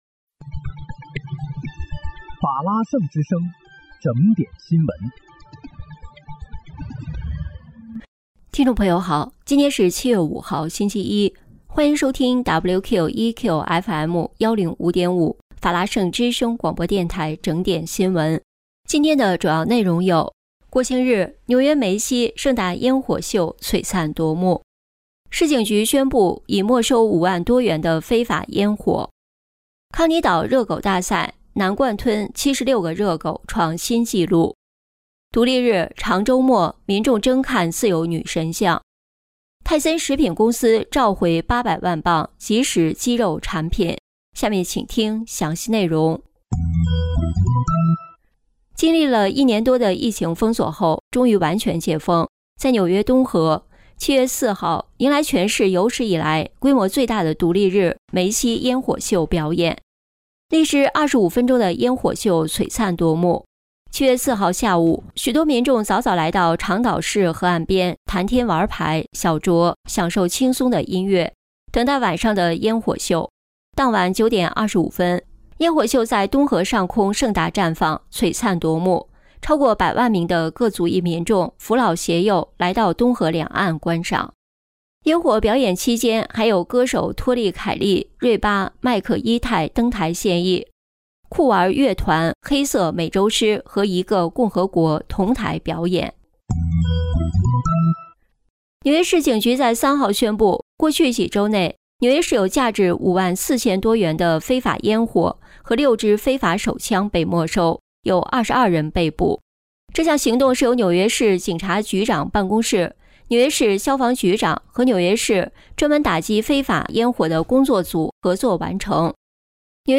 7月5日（星期一）纽约整点新闻